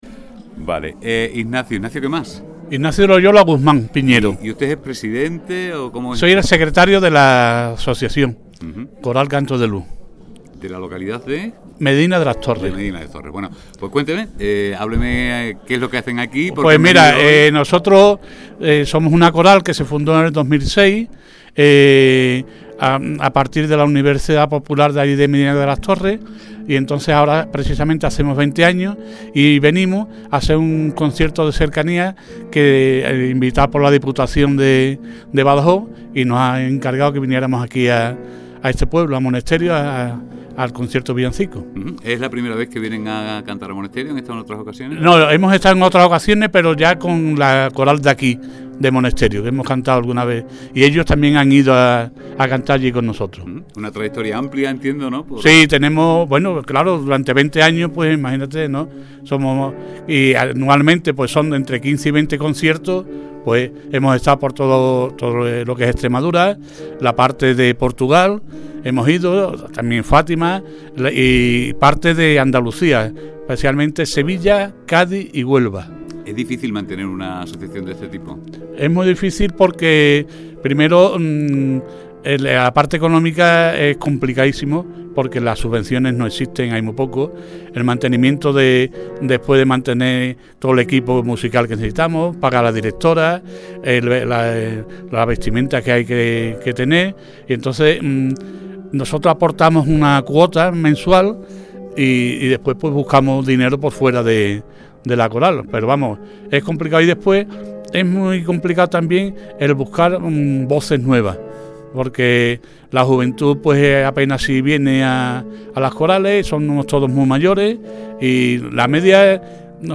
Recital de poseía y música en la Navidad de Monesterio
FMS9hCORALMEDINADELASTORRES2025.mp3